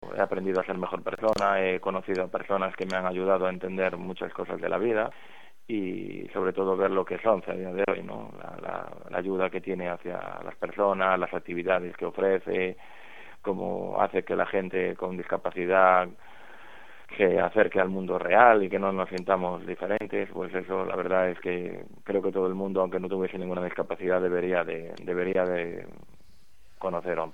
con la emoción contenida formato MP3 audio(0,52 MB).